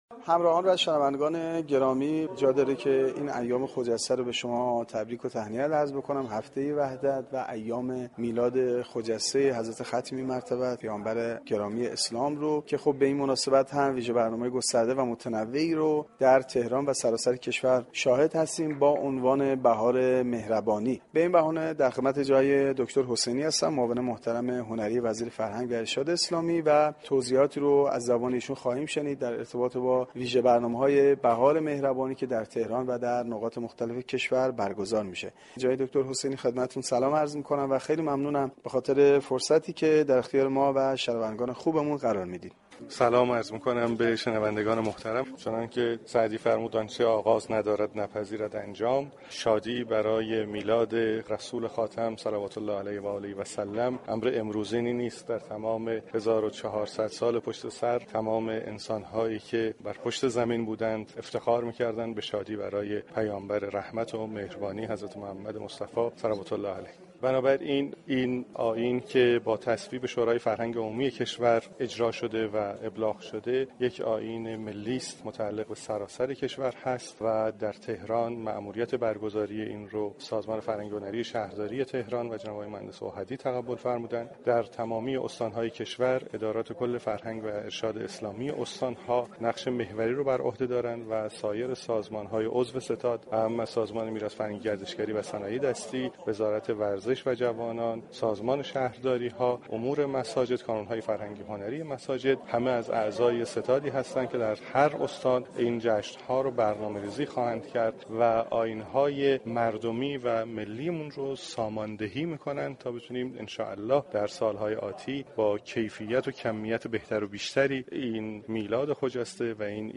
به مناسبت هفته ی وحدت و میلاد با سعادت پیامبر گرامی اسلام (ص) و امام صادق (ع) ویژه برنامه هایی تحت عنوان « بهار مهربانی » در سراسر كشور برگزار می شود . دكتر محمد مجتبی حسینی معاون هنری وزیر فرهنگ و ارشاد اسلامی در گفتگو با گزارشگر رادیو فرهنگ درباره ی جزییات برگزاری این برنامه در سراسر كشور گفت : شادی برای میلاد رسول خاتم (ص) امر امروزی نیست و در طول 1400 سال گذشته ، تمام انسانها به شادی برای پیامبر رحمت و مهربانی افتخار می كنند .